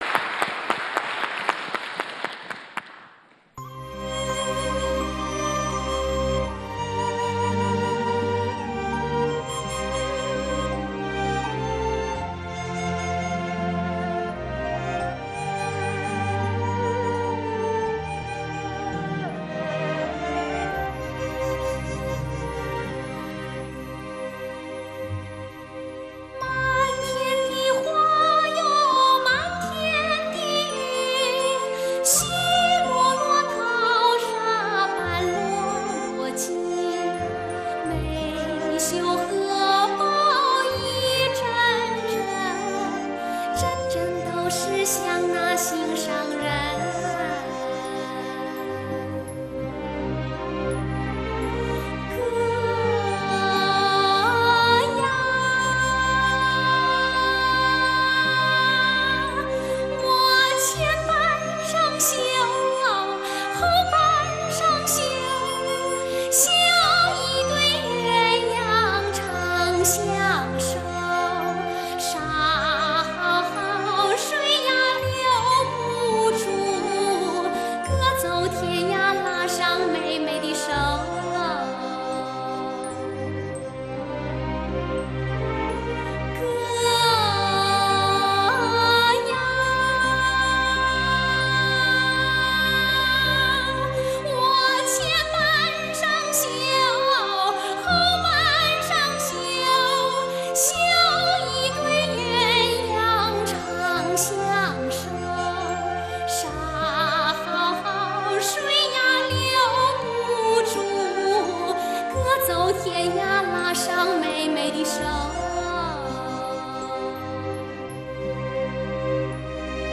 听着这歌，看着这土，我不知是想念，还是感动，只是忍不住一遍一遍的听，像是天籁，幽怨空灵......